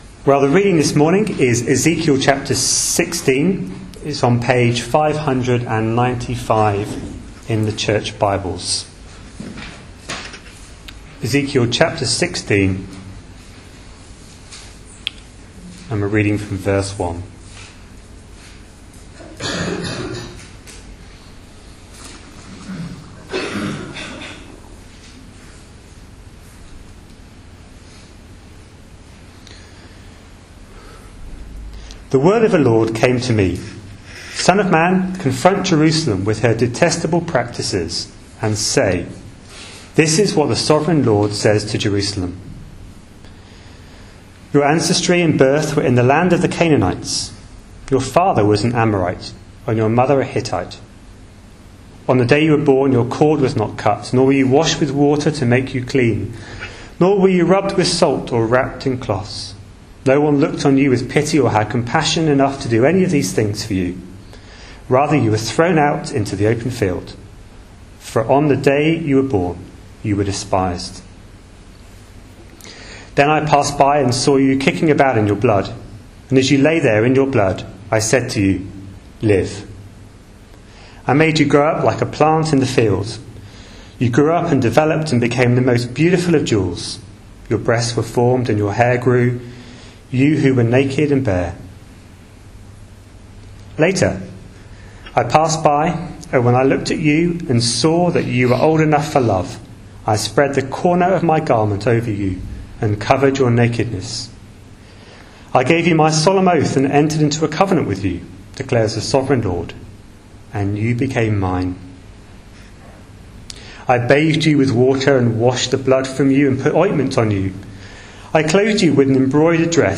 A sermon preached on 29th June, 2014, as part of our Ezekiel series.